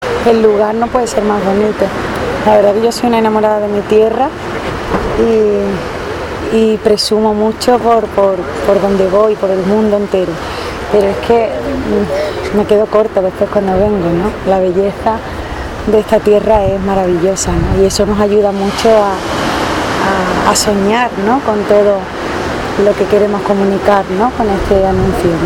Sara Baras habla sobre el rodaje del spot en Tarifa